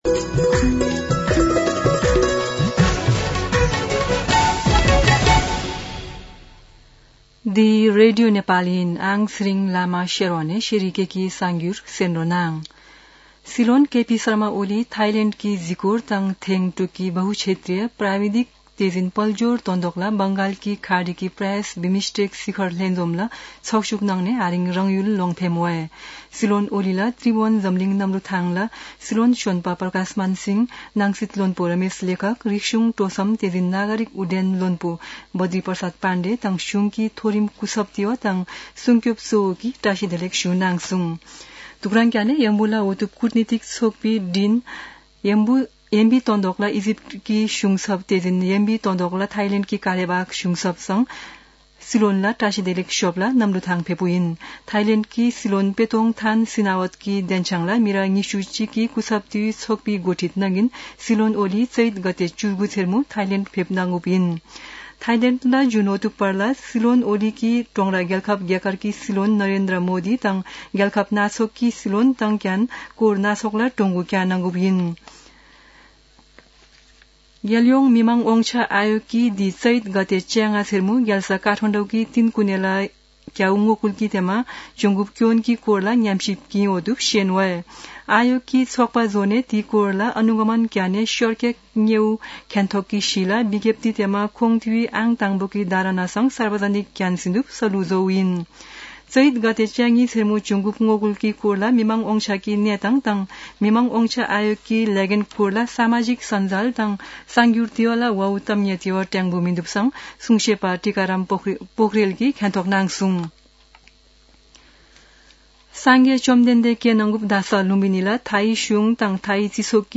An online outlet of Nepal's national radio broadcaster
शेर्पा भाषाको समाचार : २३ चैत , २०८१
Sherpa-News-2.mp3